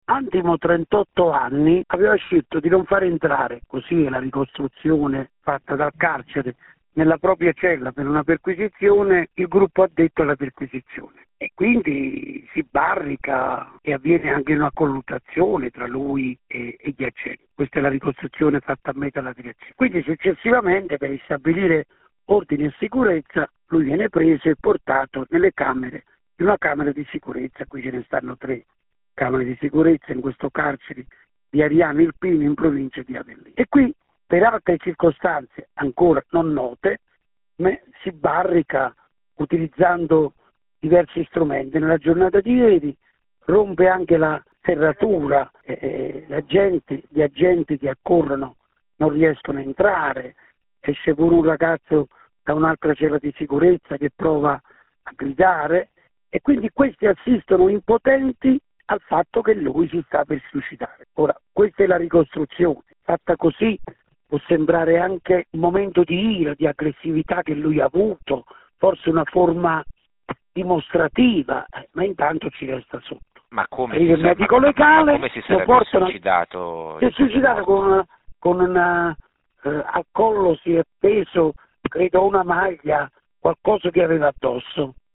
Sentiamo il garante dei detenuti della regione Campania e portavoce nazionale dei garanti Samuele Ciambriello